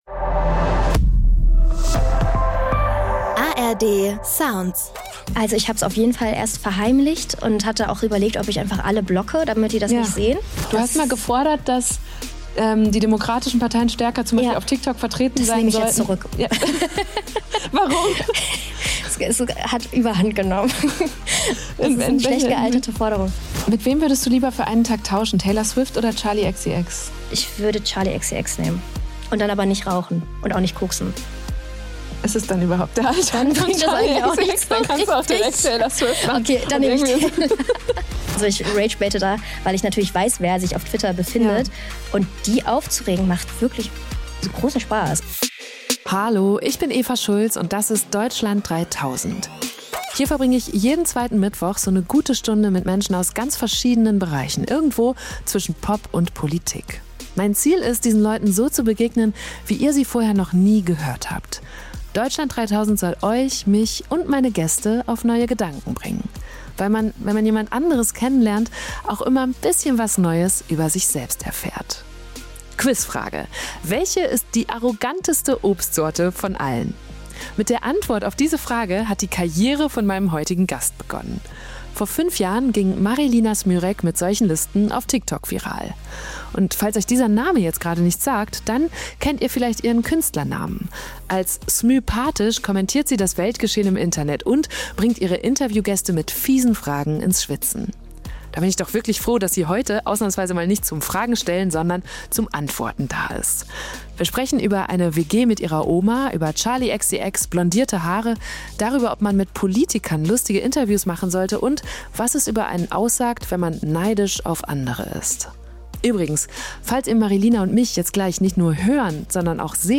Der ist noch ganz neu, das hier ist erst die zweite Folge aus meinem neuen Studio.